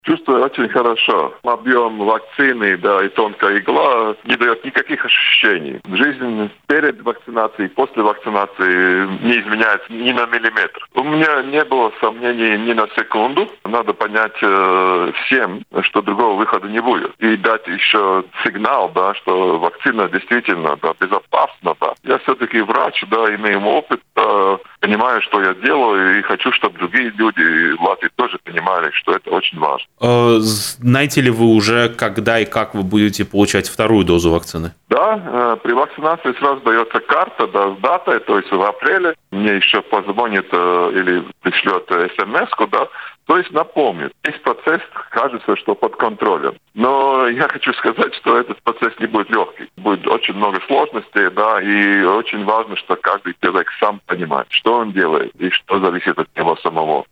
А экс-президент Латвии Валдис Затлерс рассказал в эфире радио Baltkom о своем самочувствии после вакцинации и о том, что ни секунду не сомневался в вакцинации.